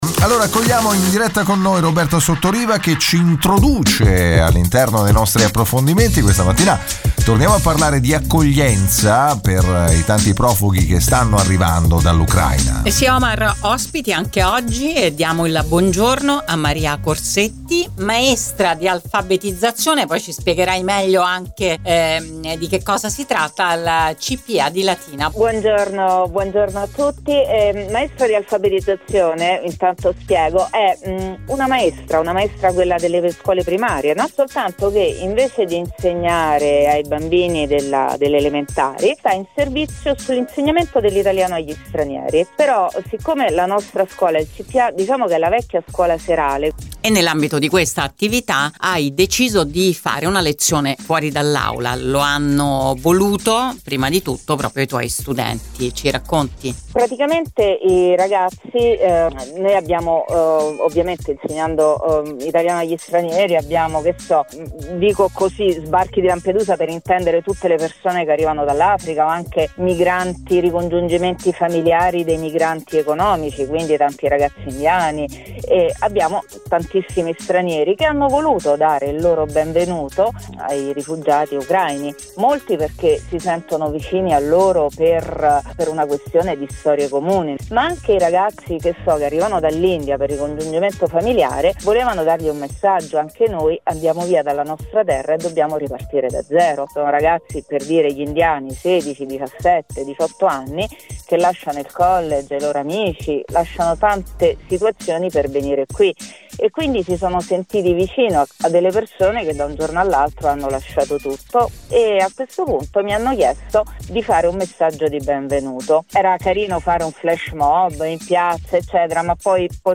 ci ha raccontato su Radio Immagine